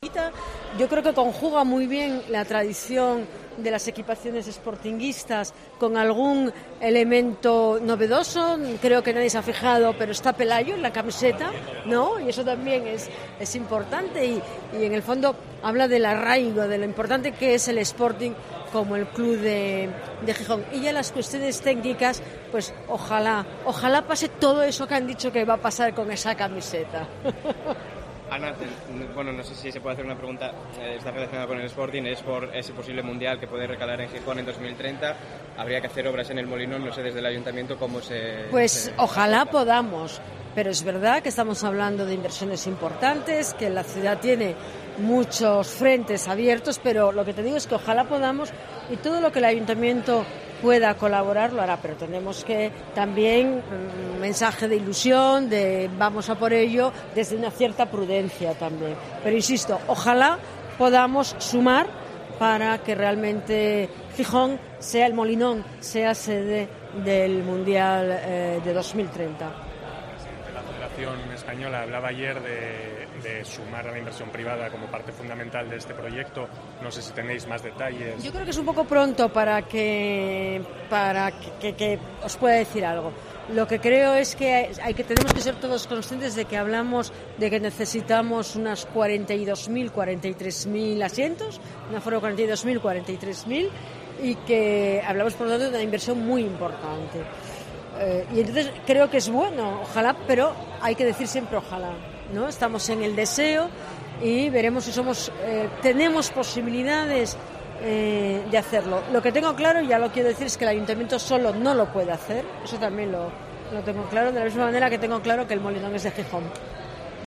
La Alcaldesa ha hablado durante la presentación de la camiseta del Sporting de lo que supondría que El Molinón fuera sede mundialista